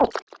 eatGulp.ogg